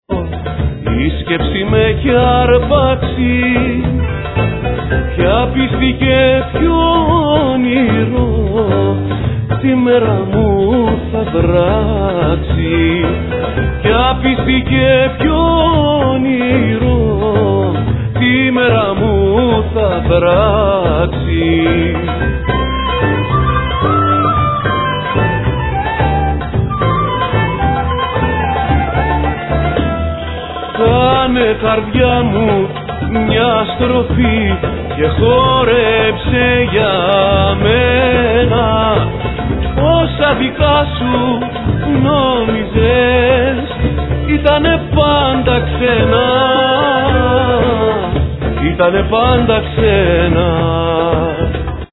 Kanun
Ney
Oud, Saz, Bulgari, Baglamas, Tar, Rain stick
Bouzouki
Violin, String quartet, String ensemble